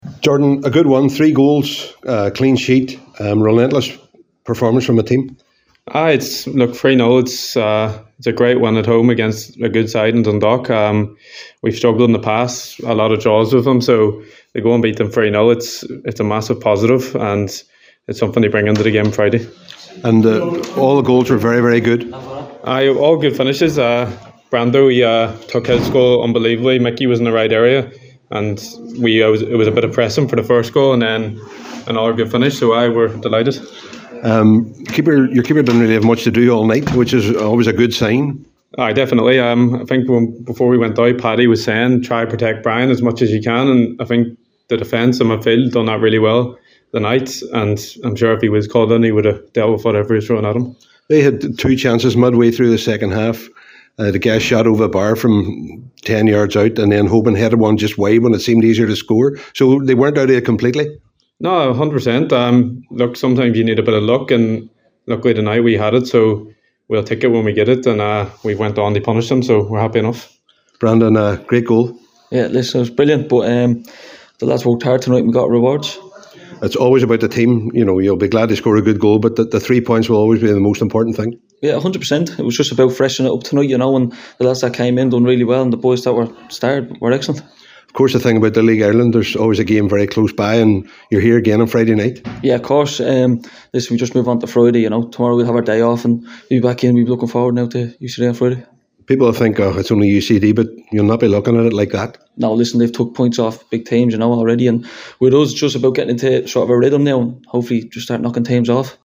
spoke with goalscorers